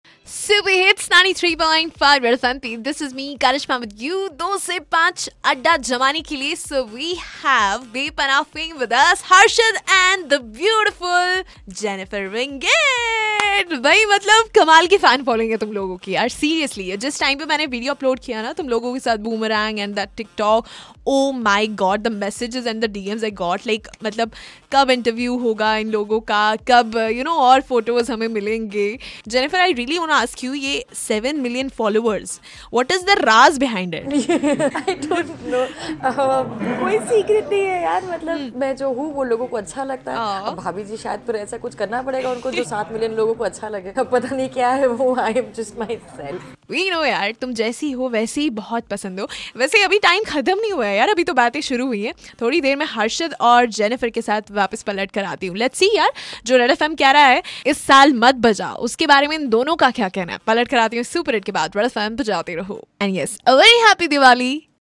CHIT CHAT WITH BEPANAH STAR CAST-JENNIFER WINGET & HARSHAD CHOPDA-1